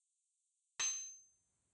sword_hit.wav